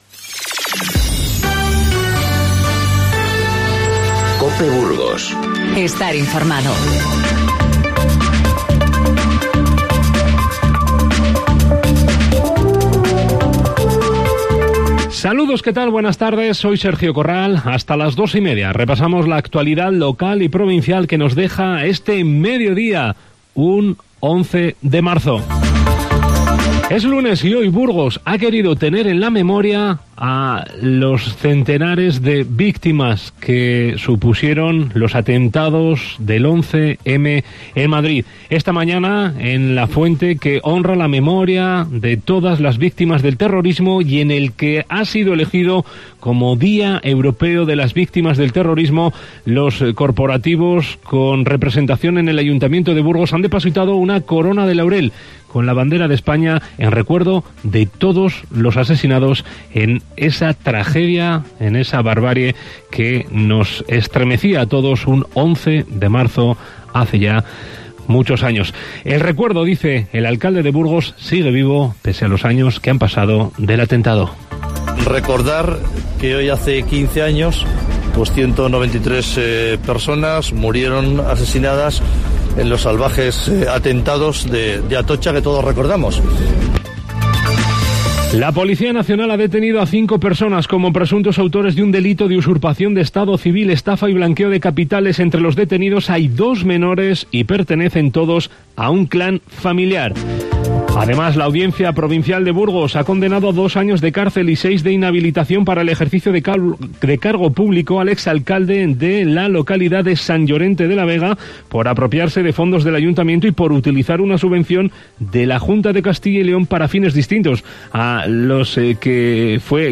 Informativo Mediodía COPE Burgos 11/03/19